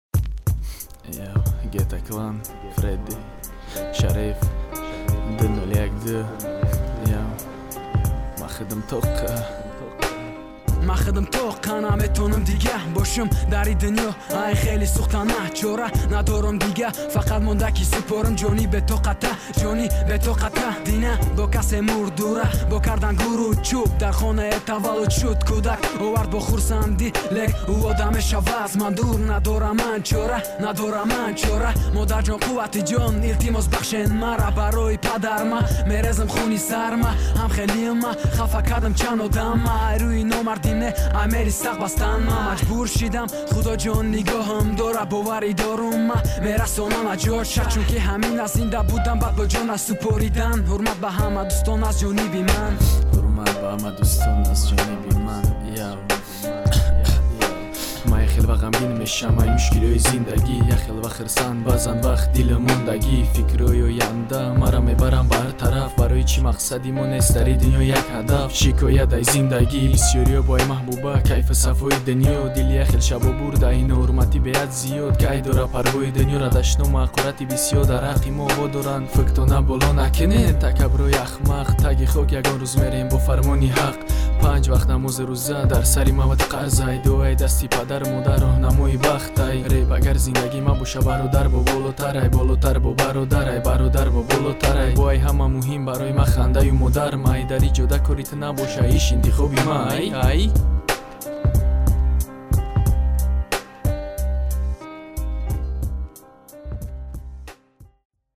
Категория: Тадж. Rap